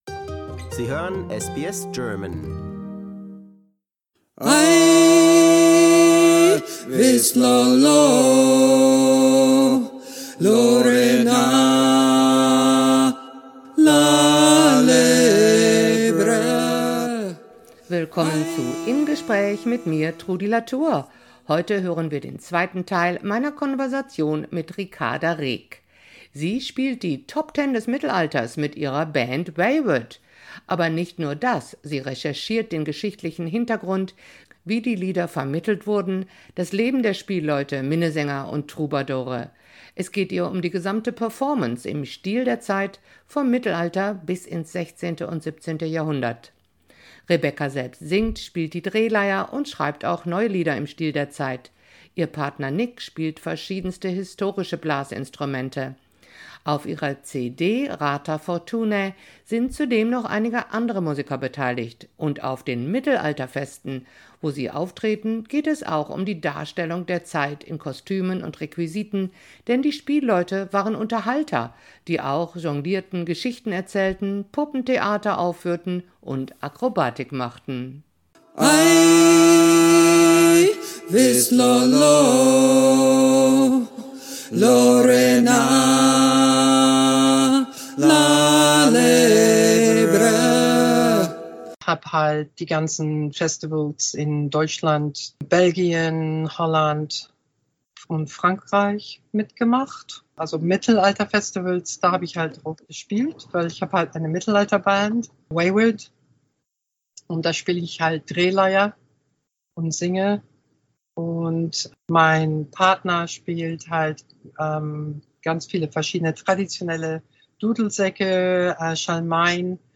German bard performs: the Top 10 of the middle ages 1/2